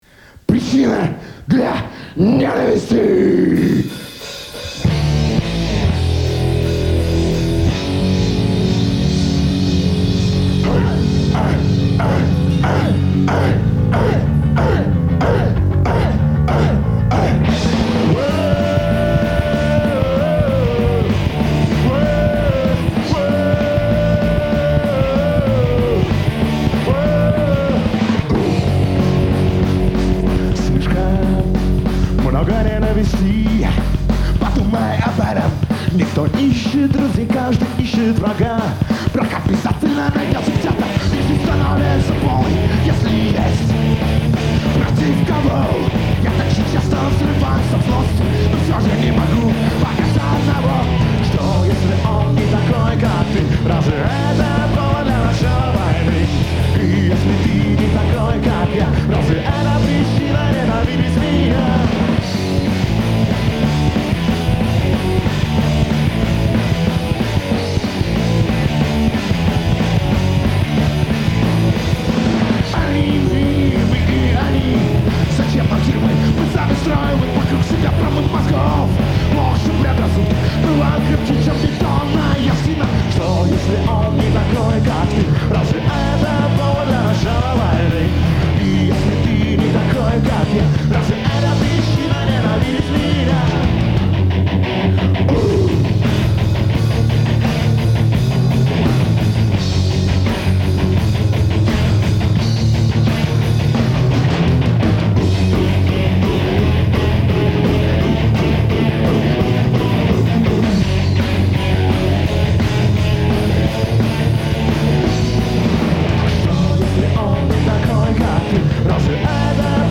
Нашёл и оцифровал кассету